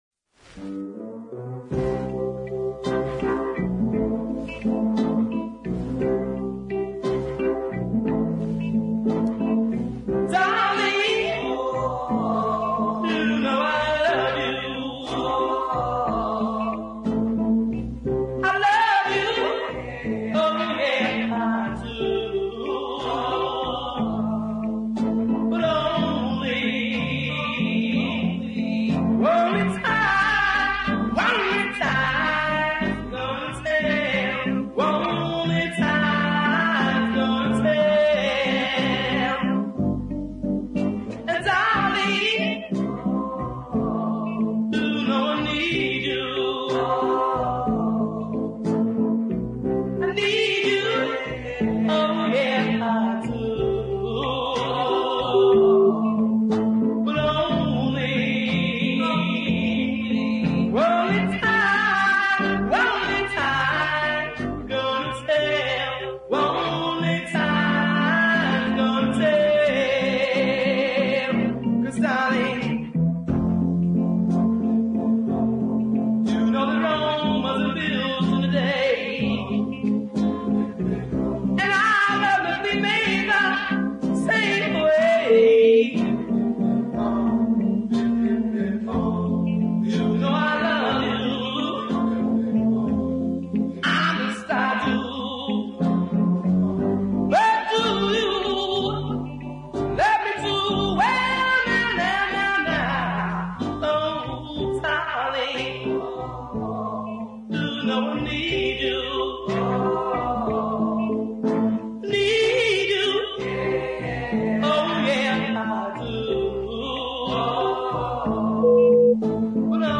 West Coast pianist and singer
superbly effective tenor vocals
the gentle male chorus
Doo-wop meets deep soul.